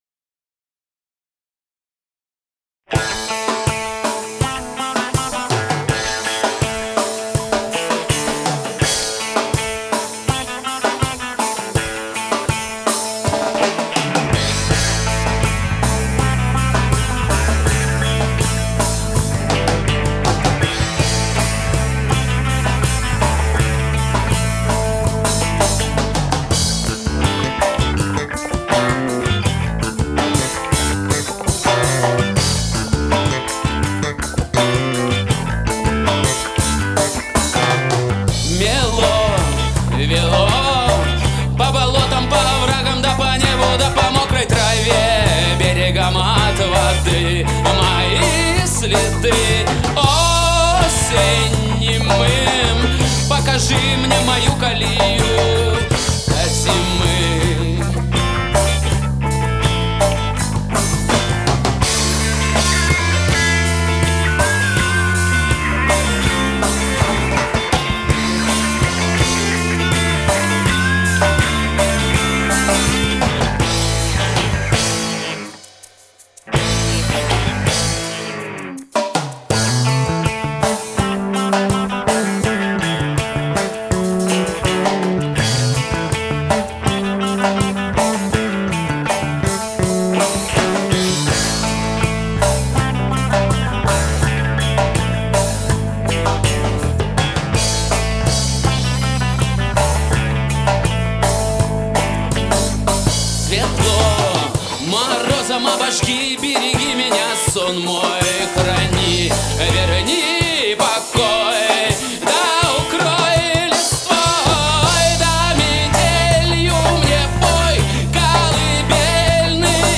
P.P.S. Демо от меня:
Трек ниже весь записан на микрофон AKG Perception 170 (ударная установка, перкуссия, гитарные комбики, голос), за исключением бас-гитары, которая записана в линию.